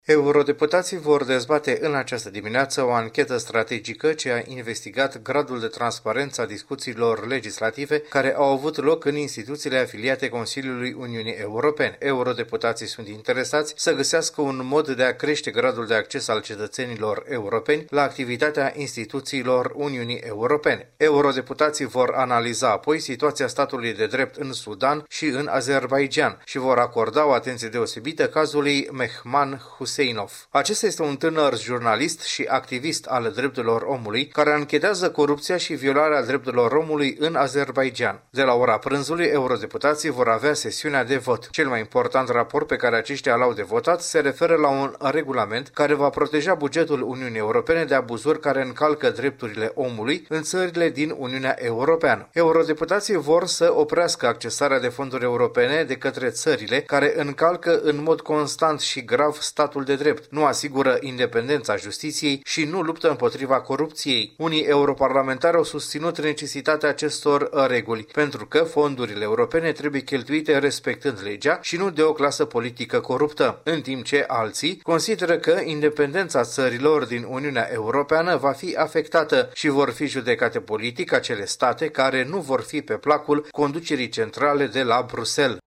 În prima parte a zilei sunt programate o serie de dezbateri, iar la prânz – sesiunea de vot final. Cu detalii, trimisul special